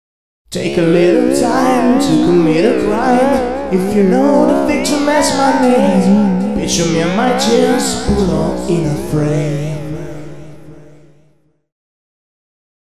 Here are two sample files recorded using Hammer and some effect patches included in the Hammer ReFill... Check them out!
Hammer and Microphone
Hammer_Voice.mp3